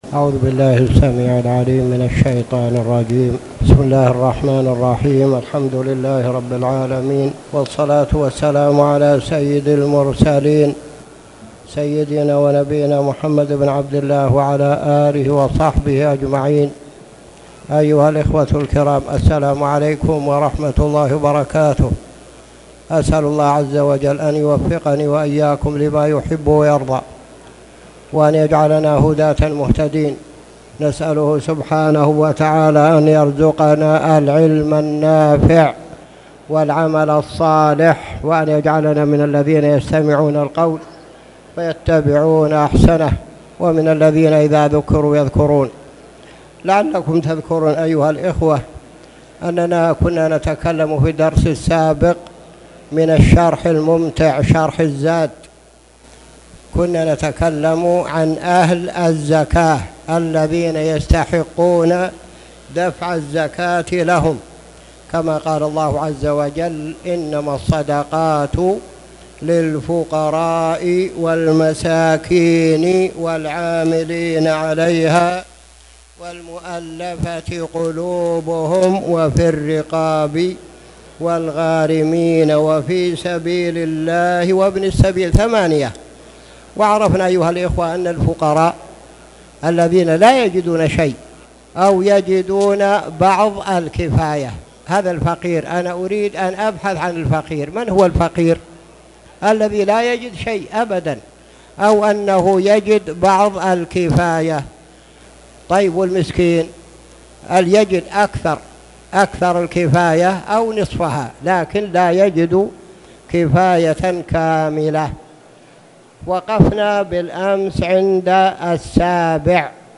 تاريخ النشر ١٢ رجب ١٤٣٨ هـ المكان: المسجد الحرام الشيخ